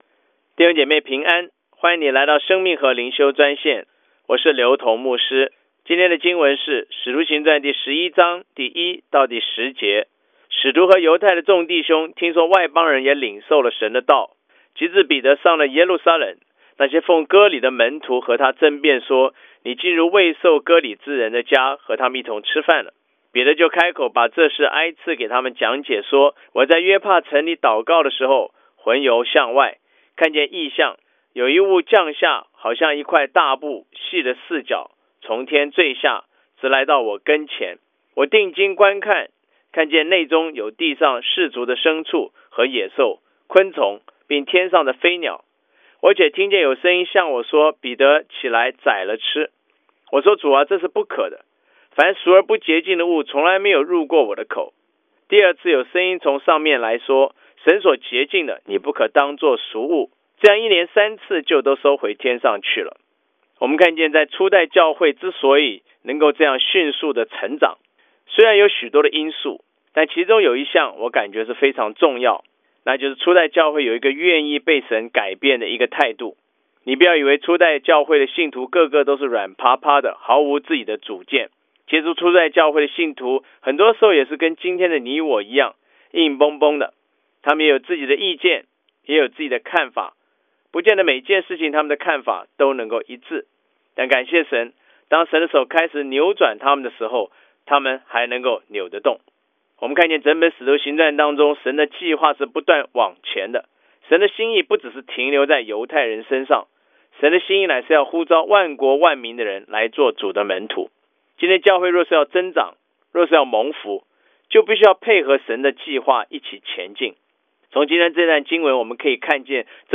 以生活化的口吻带领信徒逐章逐节读经。